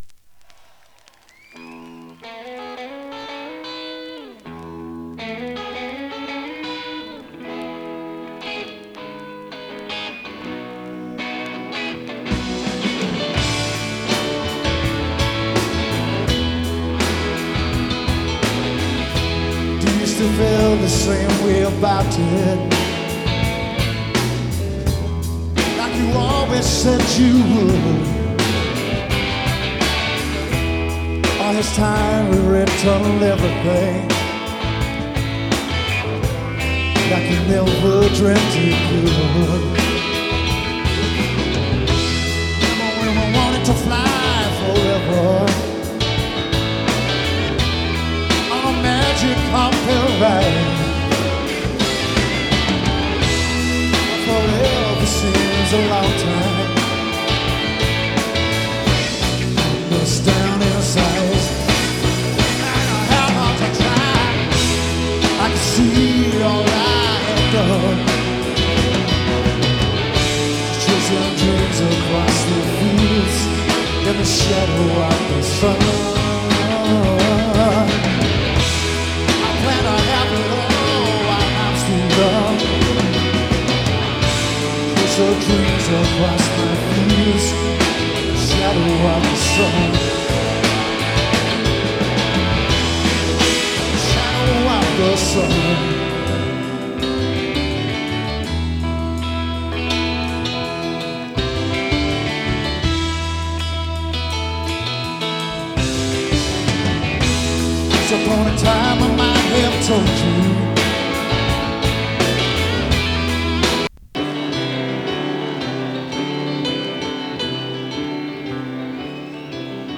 [3track 7inch]＊音の薄い部分で時おり軽いチリパチ・ノイズ。
Live At Wolverhampton